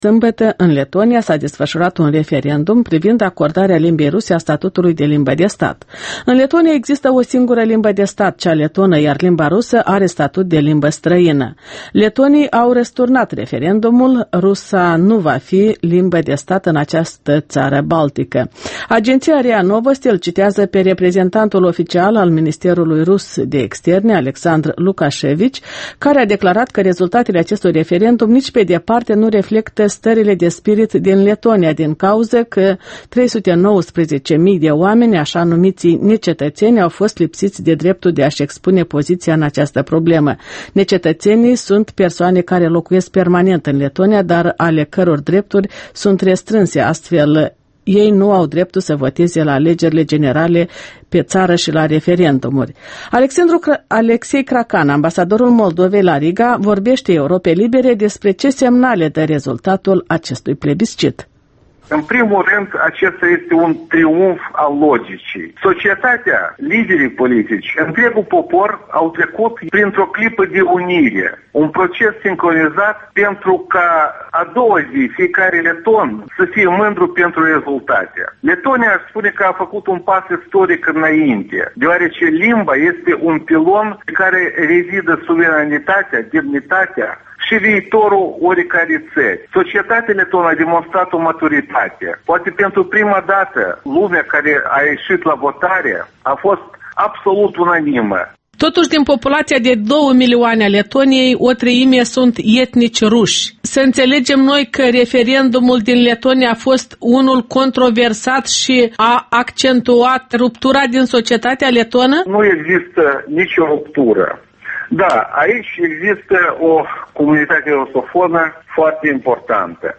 Interviul dimineții la Europa Liberă: cu ambasadorul Alexandru Cracan despre referendumul leton